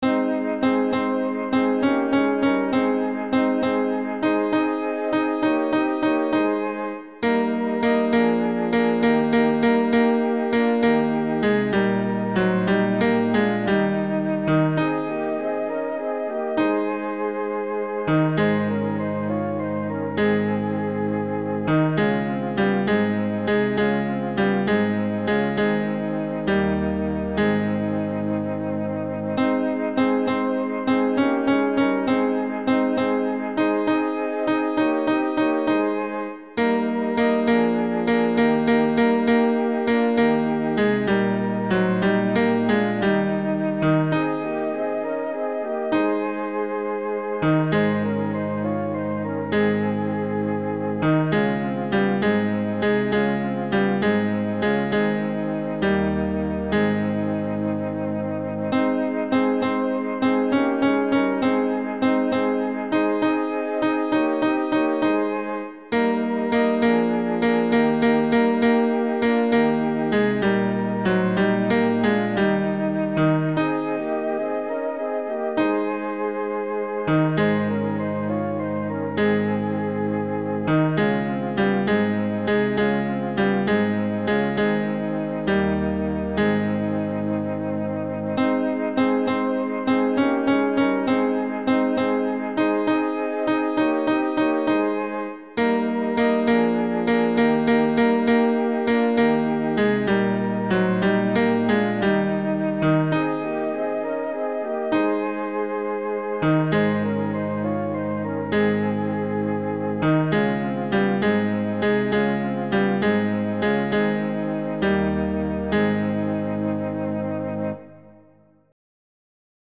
tenor
Le-Noel-des-Bergers-tenor.mp3